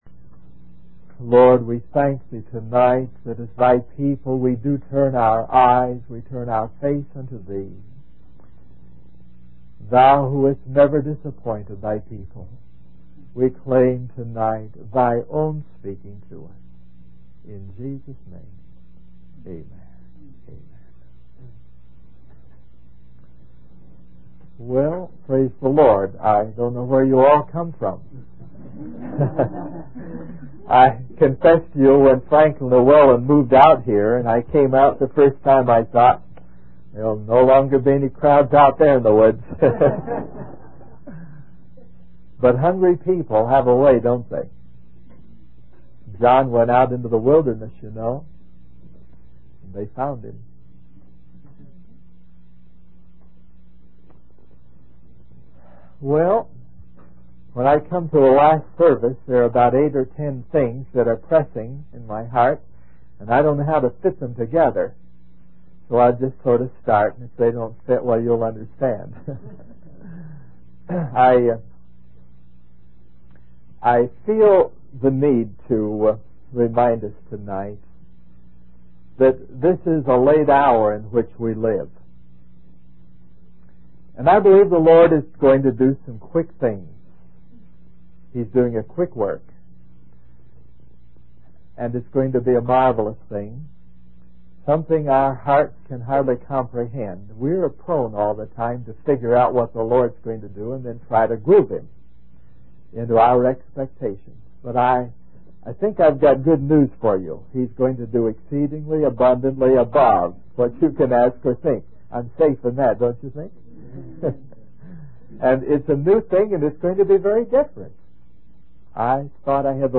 In this sermon, the speaker begins by acknowledging that there are several pressing matters on his heart that he wants to address. He emphasizes that we are living in a late hour and that God is going to do something quick and marvelous that we can hardly comprehend.